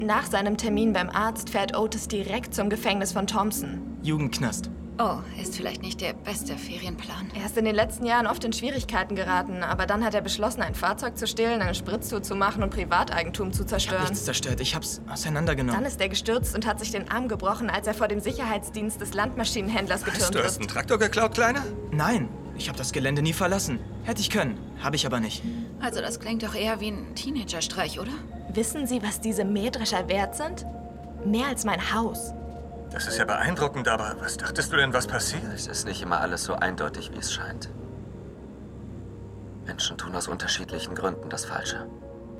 hell, fein, zart
Jung (18-30)
Eigene Sprecherkabine
Lip-Sync (Synchron), Scene